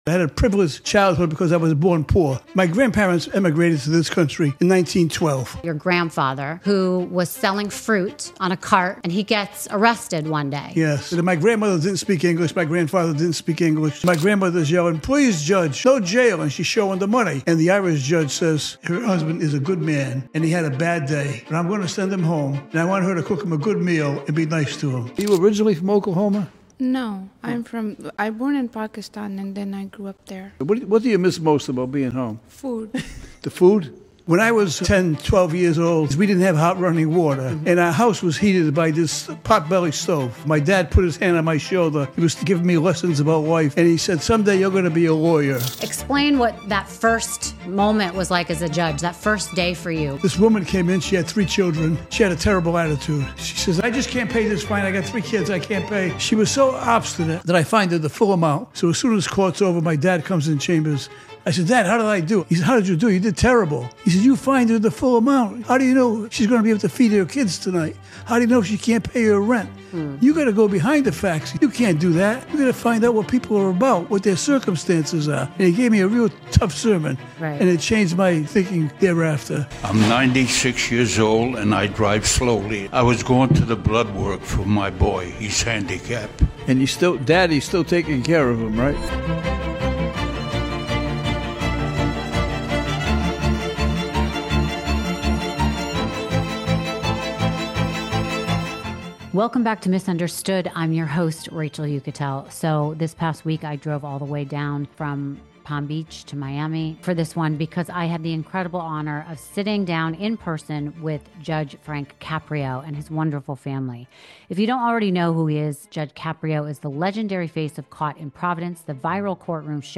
This episode with Judge Frank Caprio is a heartfelt and inspiring conversation with one of the most beloved judges in the world.